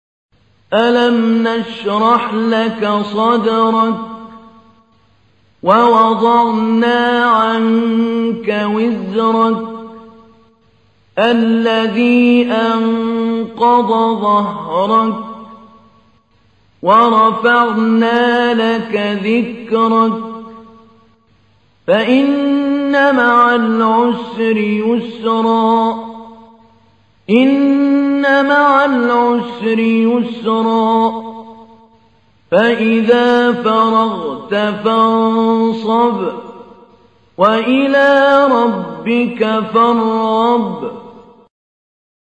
تحميل : 94. سورة الشرح / القارئ محمود علي البنا / القرآن الكريم / موقع يا حسين